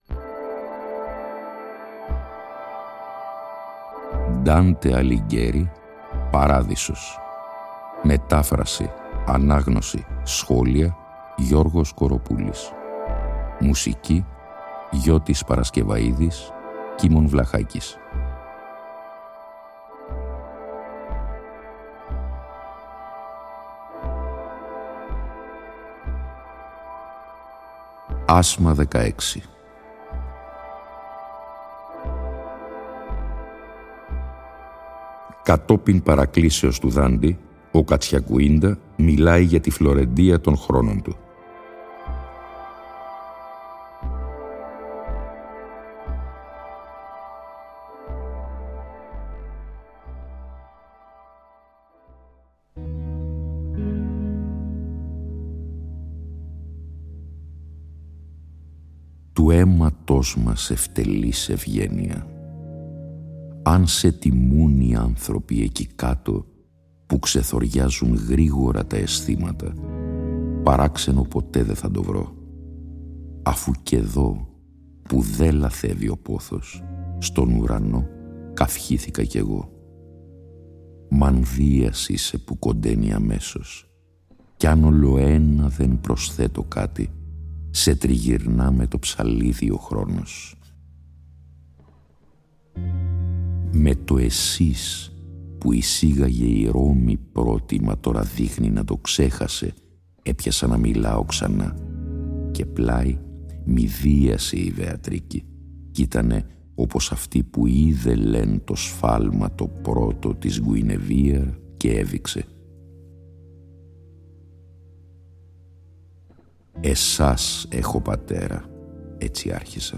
Η ανάγνωση των 33 ασμάτων του «Παραδείσου», σε 20 ημίωρα επεισόδια, (συνέχεια της ανάγνωσης του «Καθαρτηρίου», που είχε προηγηθεί) συνυφαίνεται και πάλι με μουσική